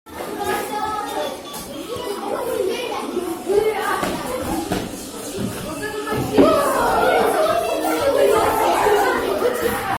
Férias escolares 41769
• Categoria: Pausa na escola 1301